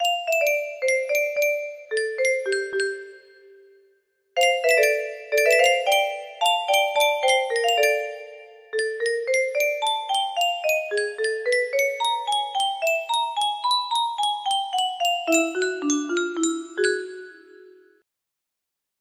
Some music box melody